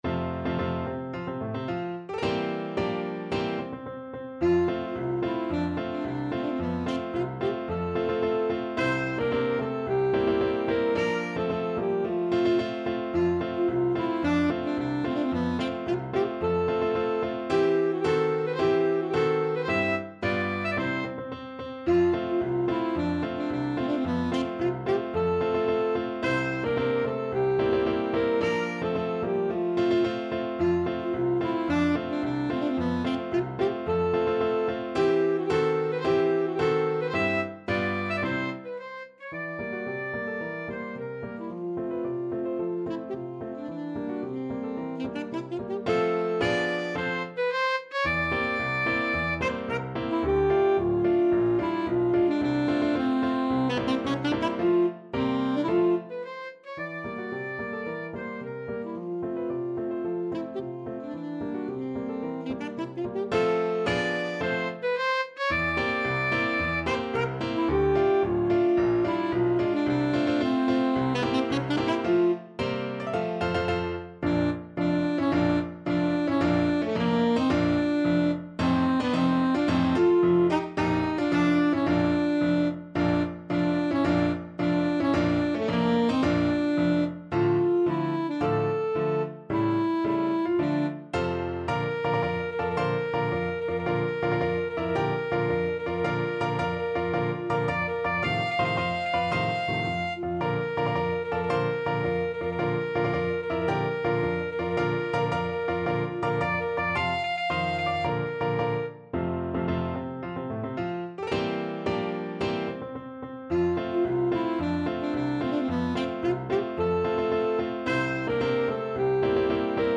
Classical Komzak, Karel Erzherzog Albrecht March Alto Saxophone version
F major (Sounding Pitch) D major (Alto Saxophone in Eb) (View more F major Music for Saxophone )
2/2 (View more 2/2 Music)
March =c.110
Saxophone  (View more Intermediate Saxophone Music)
Classical (View more Classical Saxophone Music)